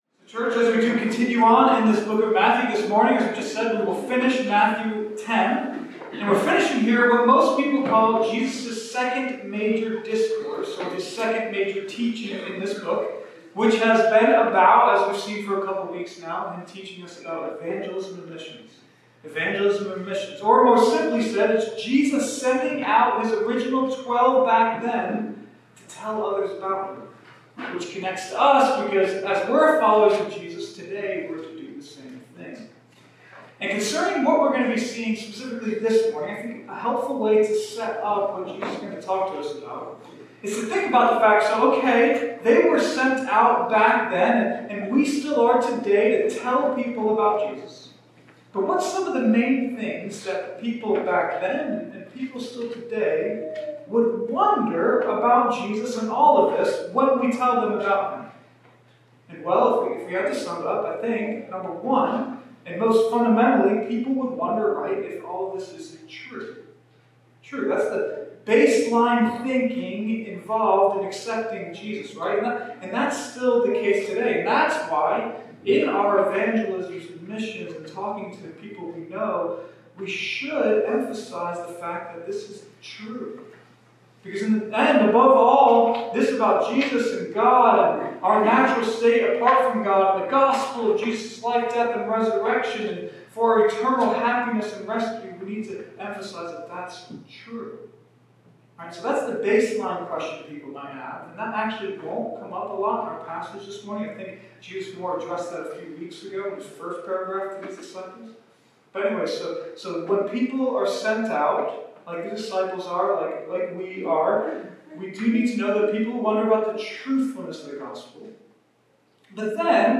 (Apologies for the poor audio recording on this message)